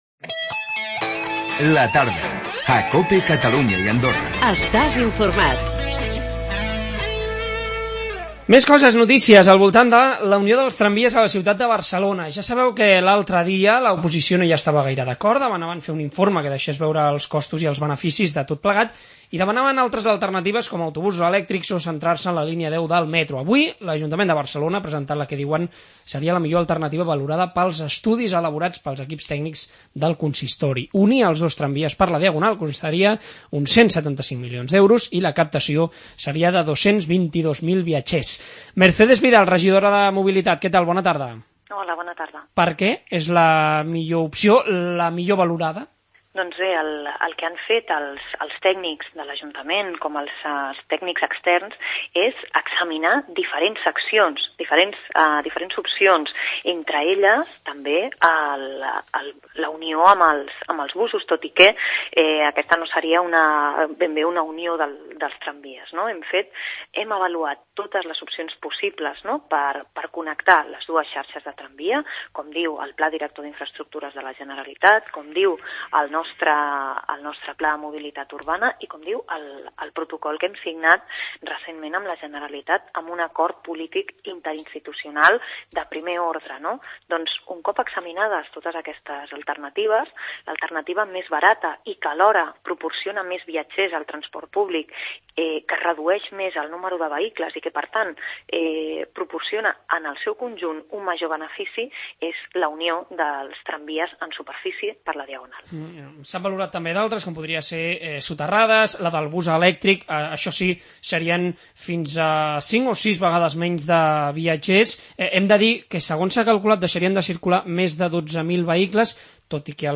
AUDIO: Unir els tramvies per la diagonal costaria 175 milions i captaria 222.000 viatgers. Hem parlat amb Mercedes Vidal, regidora de Mobilitat